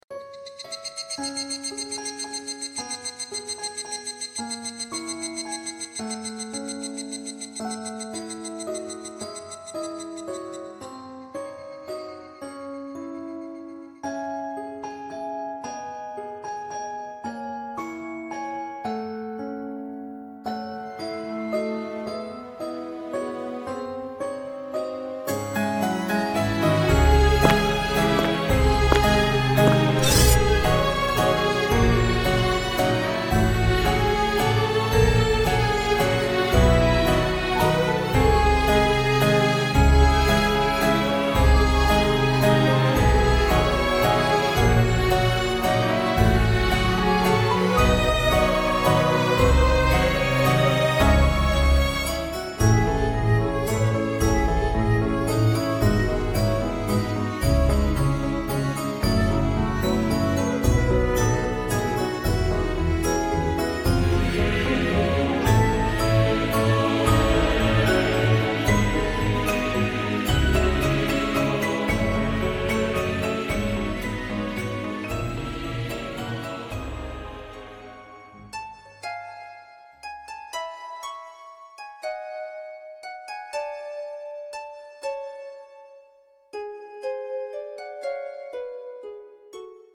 【声劇】世界に救いなど、